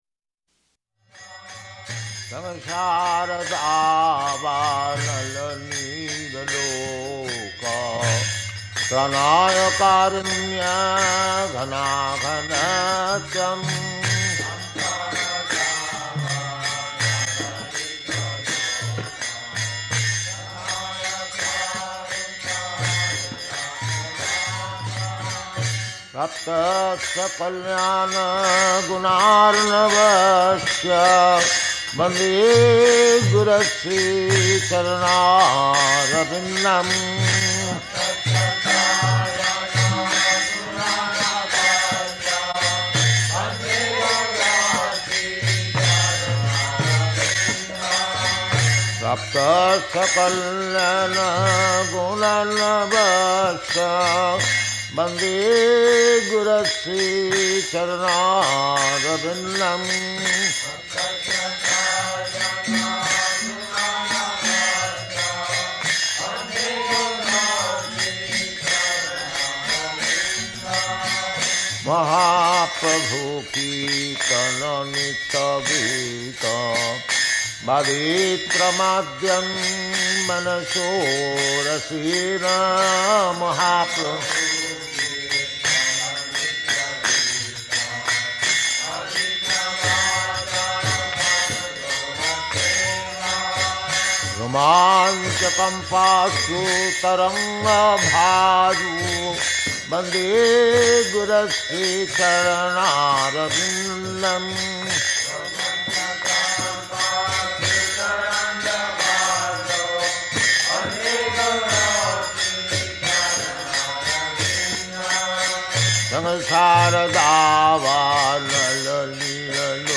Location: Hyderabad
[leads singing of Guruvaṣṭakam then Jaya Rādhā-Mādhava ]